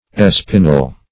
espinel - definition of espinel - synonyms, pronunciation, spelling from Free Dictionary Search Result for " espinel" : The Collaborative International Dictionary of English v.0.48: Espinel \Es"pi*nel\, n. A kind of ruby.